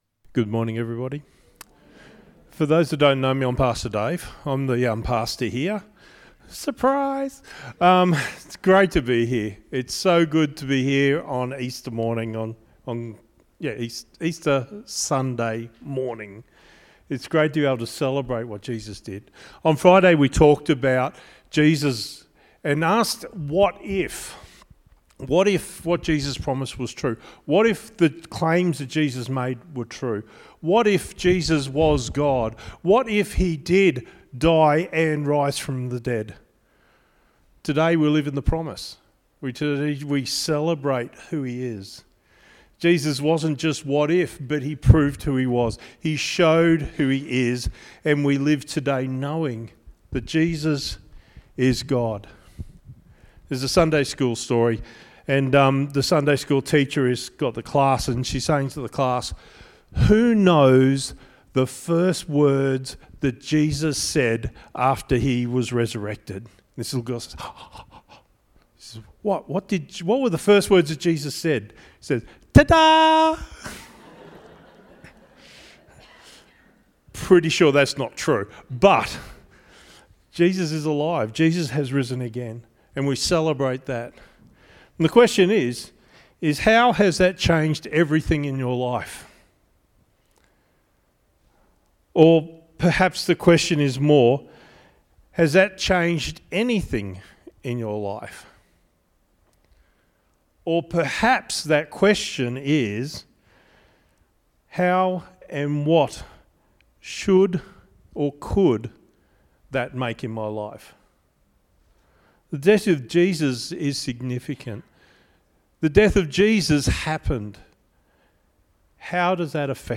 "He Is" - Easter Sunday Service - Stanthorpe Baptist Church